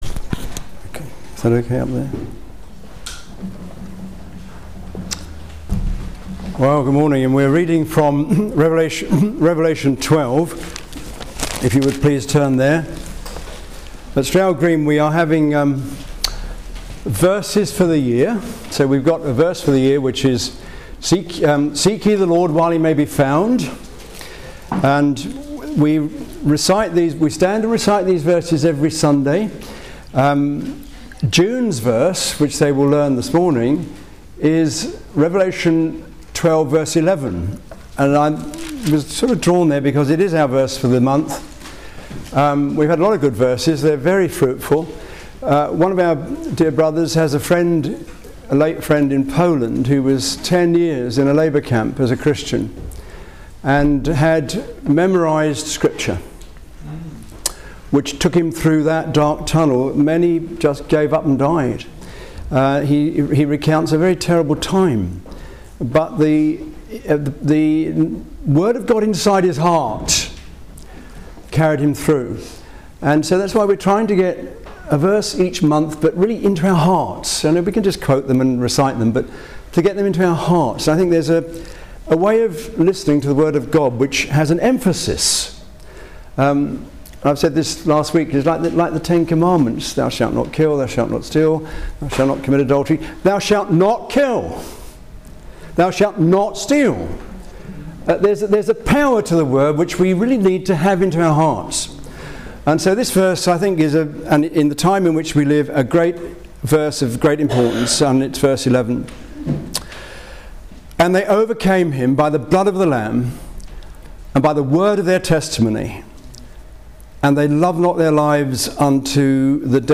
Psalm 137:1-2 Passage: Revelation 12:11 Service Type: Christian Alliance Ministries Conference « ‘Where is the Lord God of Elijah?’